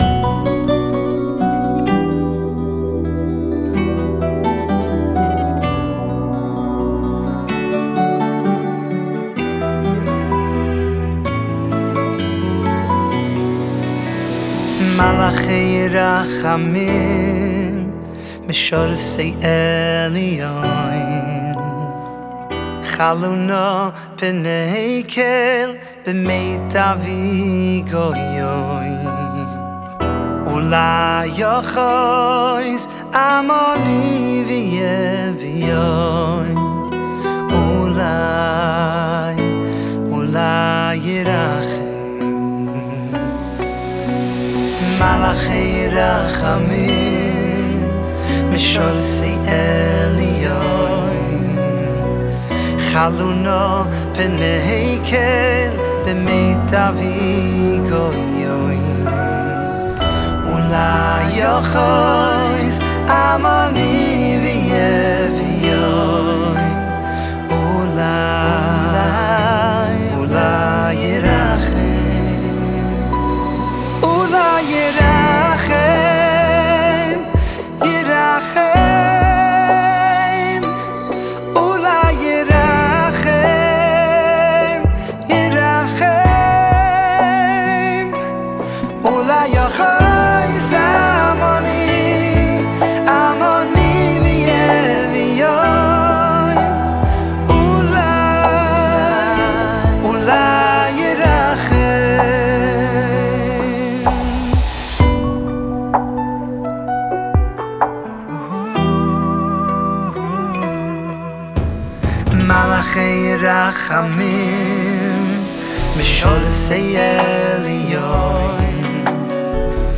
שיר חדש ומרגש, בביצוע אישי כובש ומרגש.